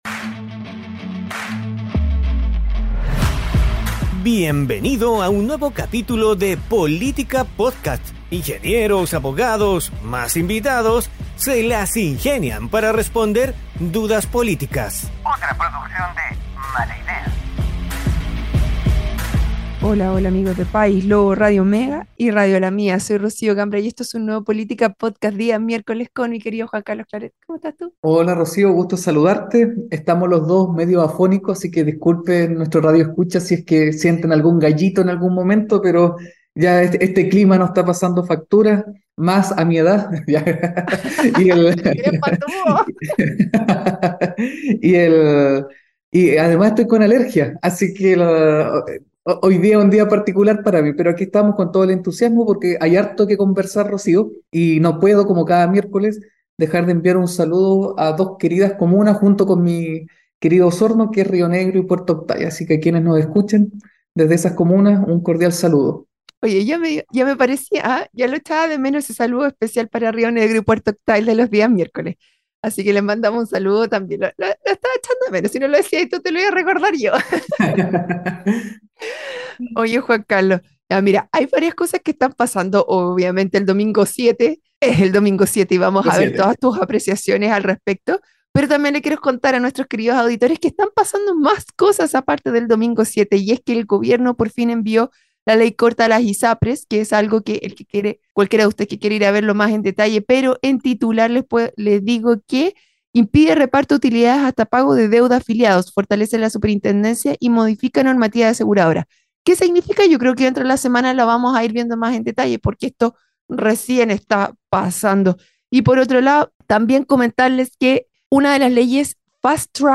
junto a panelistas estables e invitados tratan de responder dudas políticas.